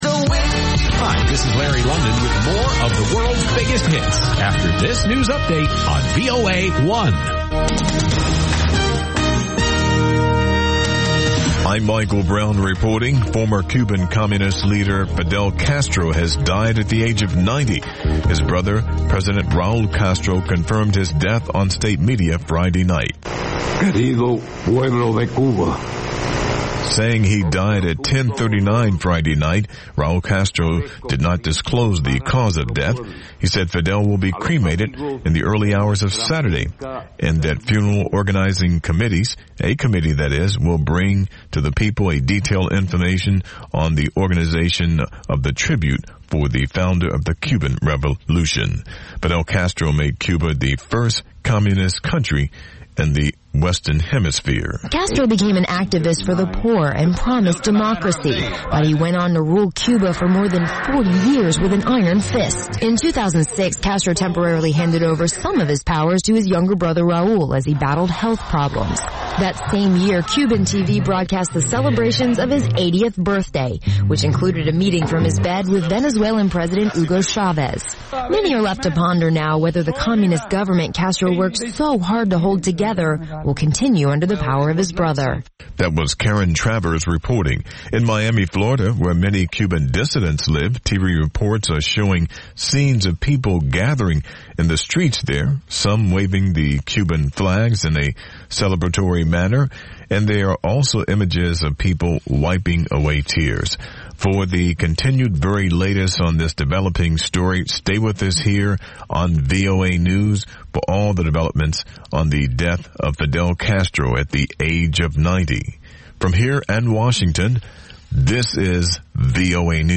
ყოველ შაბათს რადიო თავისუფლების პირდაპირ ეთერში შეგიძლიათ მოისმინოთ სპორტული გადაცემა „მარათონი“.